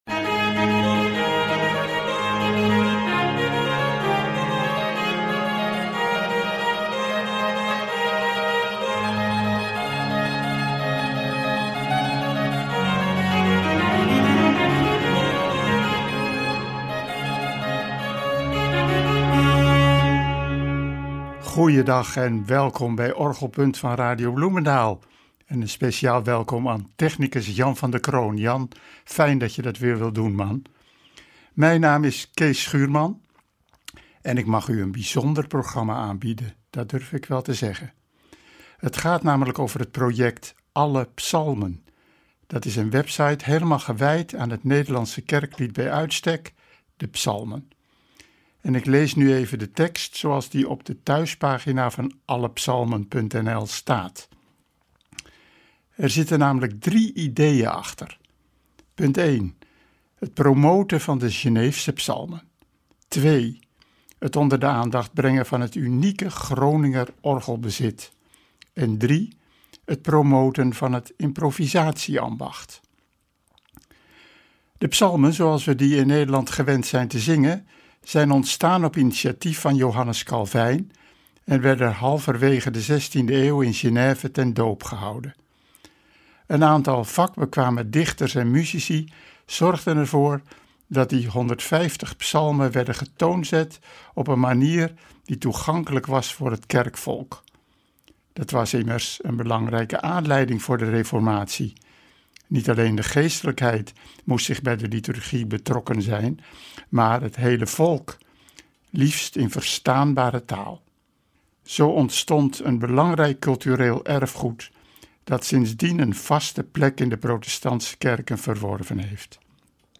een begenadigd organist en bekwaam improvisator.
improvisaties